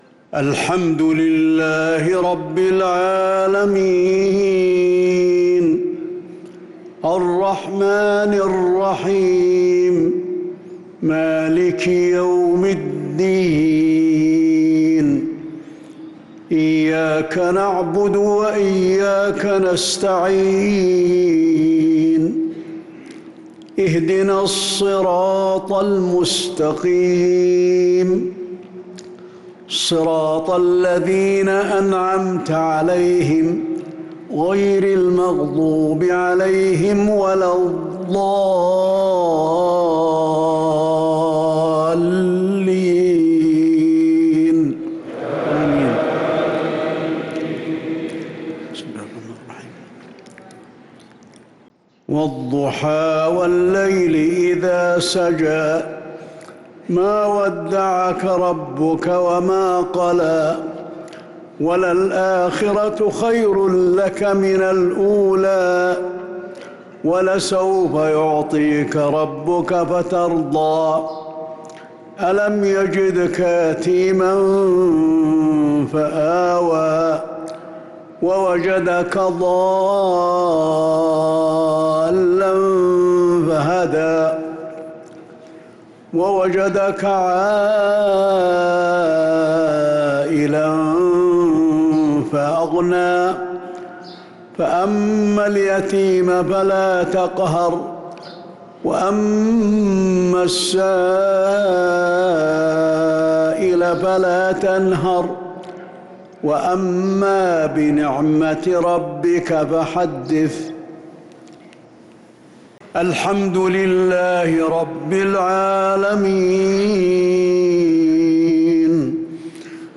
مغرب الأحد 9-9-1446هـ سورتي الضحى و الشرح كاملة | Maghrib prayer Surat ad-Duha & ash-Sharh 9-3-2025 > 1446 🕌 > الفروض - تلاوات الحرمين